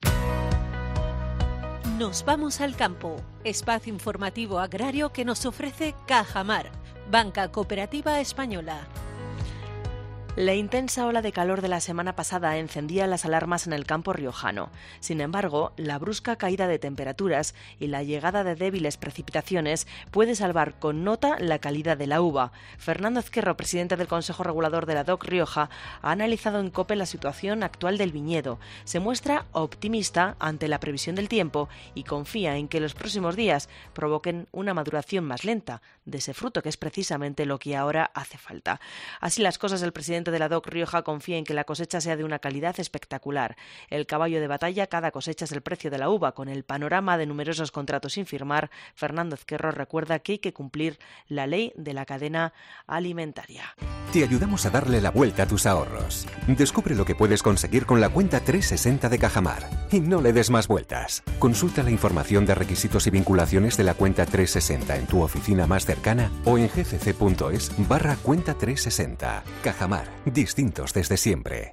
La entidad financiera 'Cajamar' patrocina cada miércoles en COPE Rioja la noticia agroeconómica de la semana